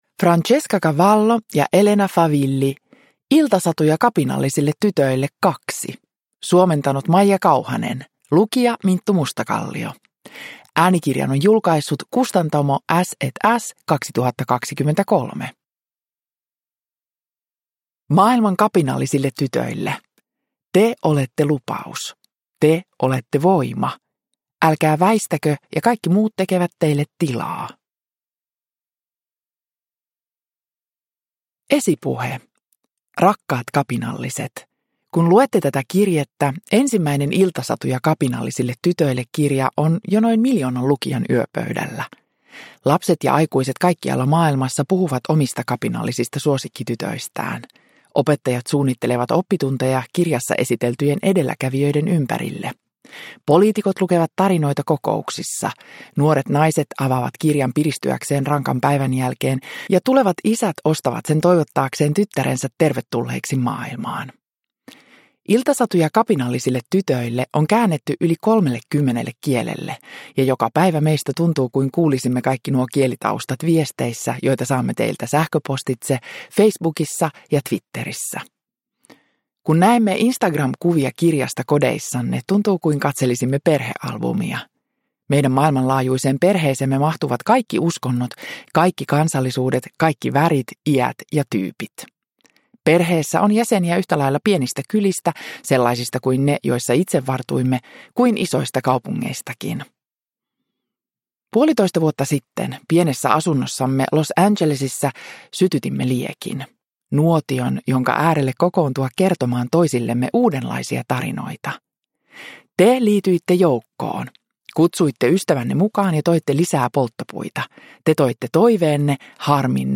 Iltasatuja kapinallisille tytöille 2 – Ljudbok – Laddas ner